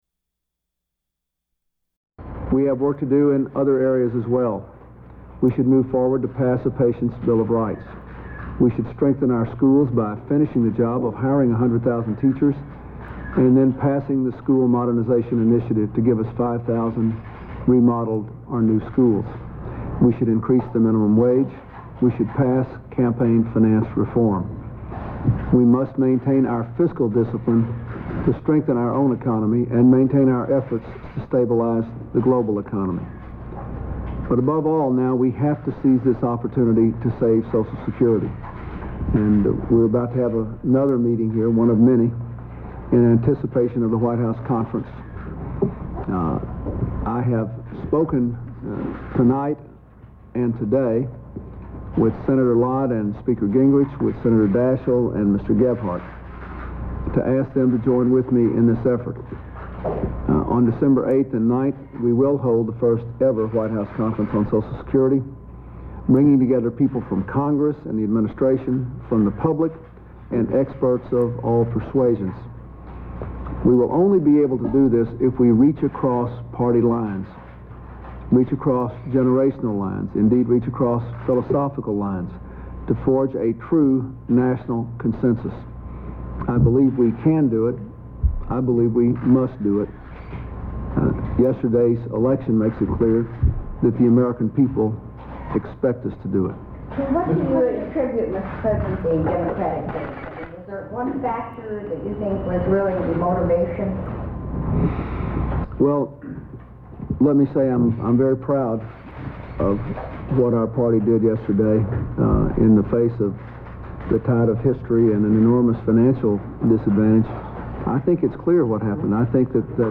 U.S. President Bill Clinton talks about his agenda following the results of the U.S. Senate and House elections